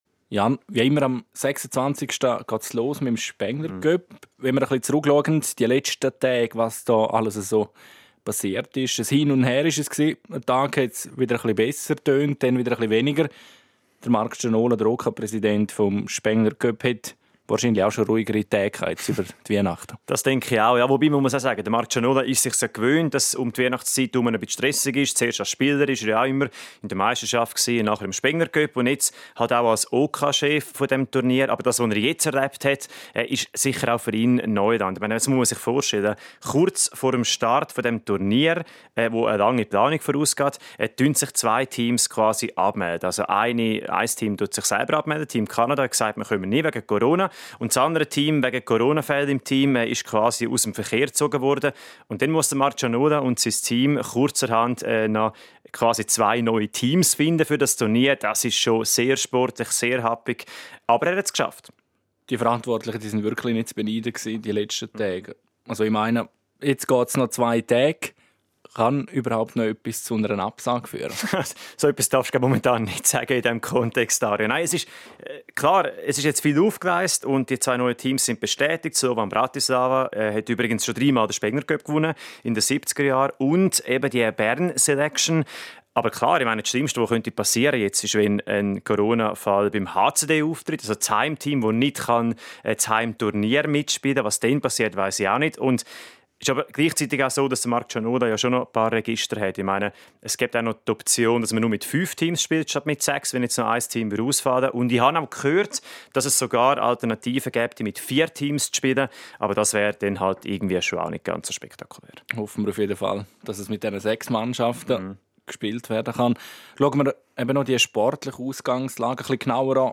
Die Spengler-Cup-Vorschau der Sportredaktoren